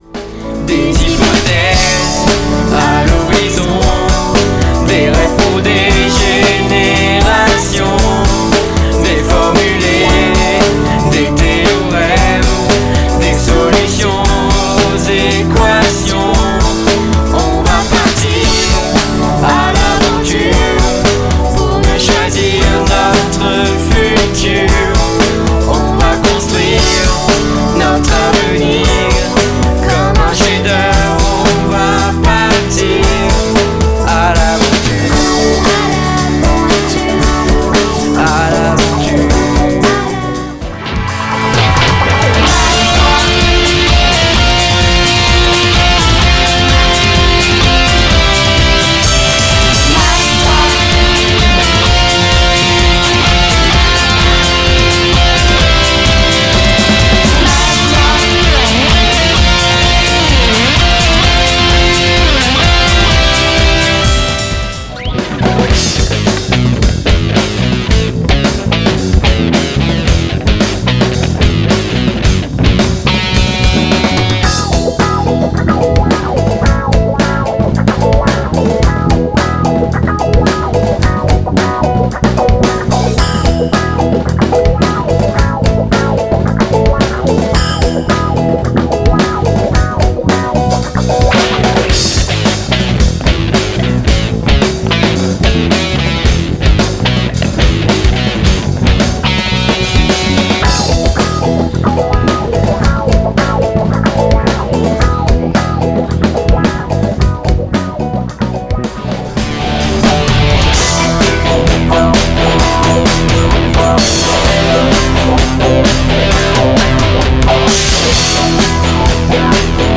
prémix avec quelques extraits en cours de mixage !